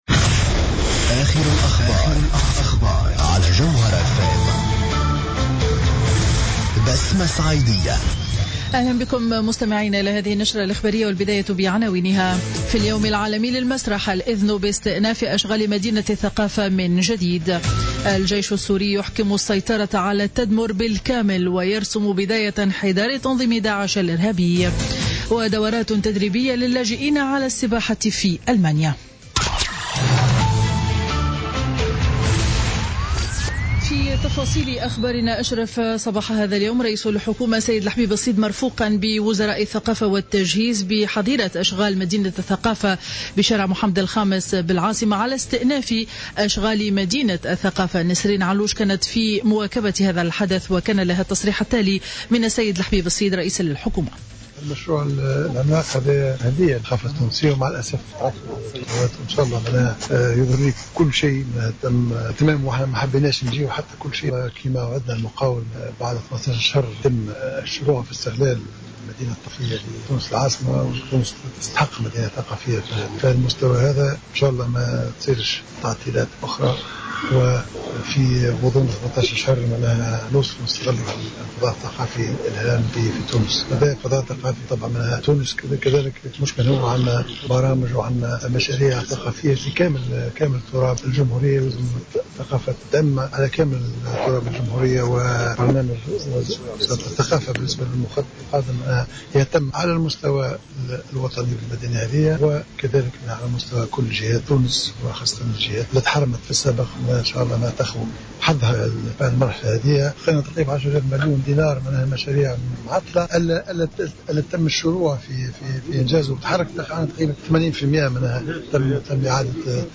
نشرة أخبار منتصف النهار ليوم الأحد 27 مارس 2016